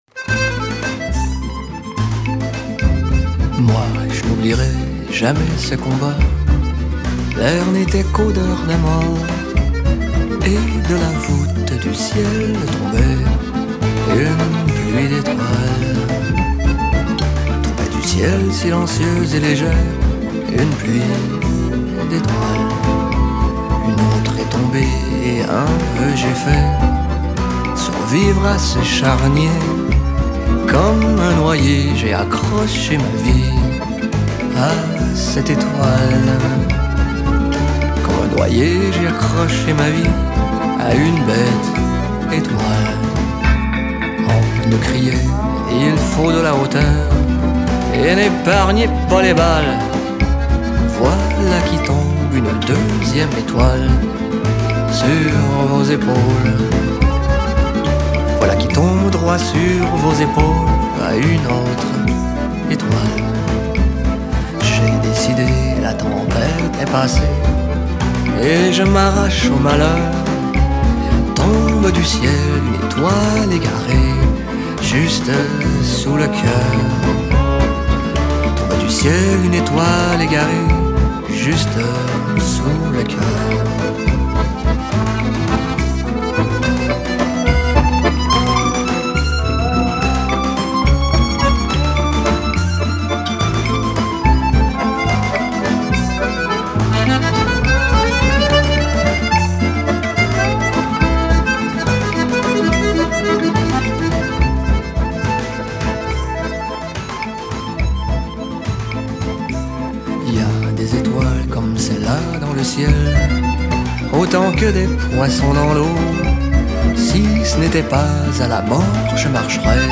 контрабас
аккордеон и скрипка
фортепиано